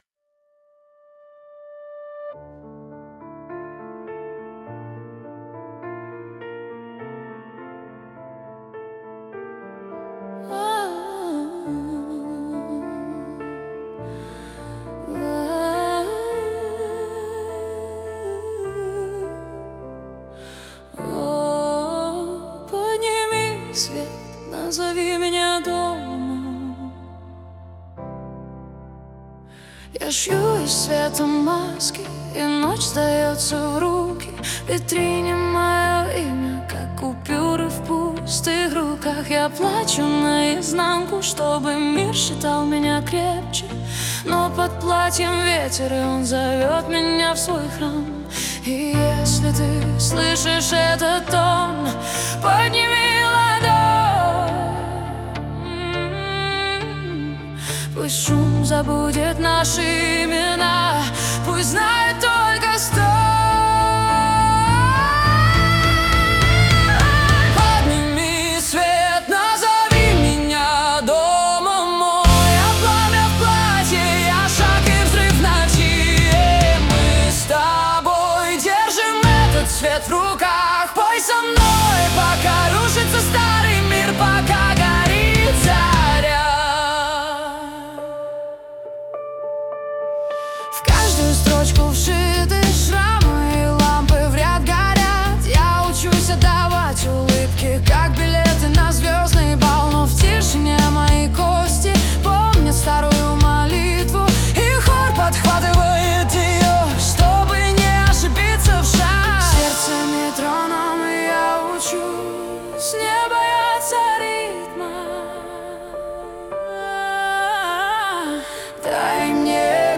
• Исполняет: Поставторcкий арт